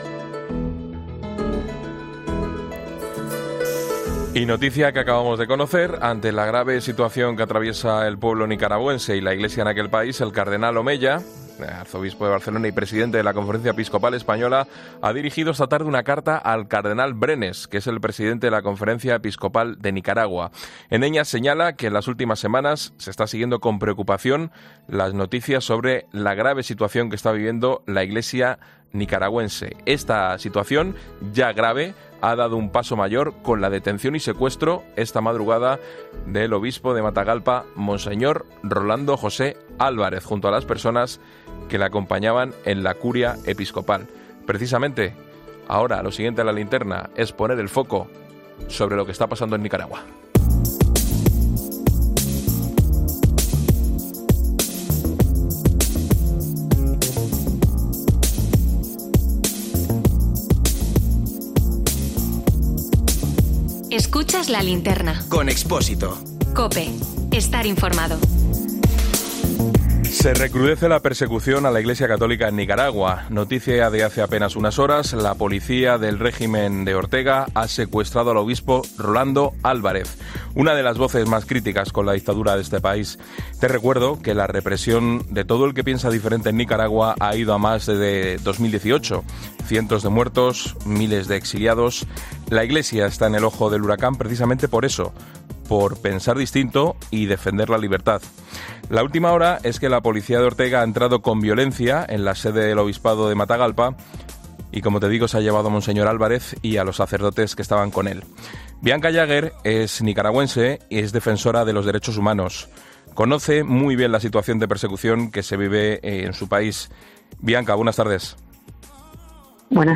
Escucha la entrevista a Bianca Jagger nicaragüense y defensora de los Derechos Humanos, en La Linterna de COPE